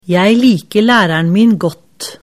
setningsdiktat_skolesystemet04.mp3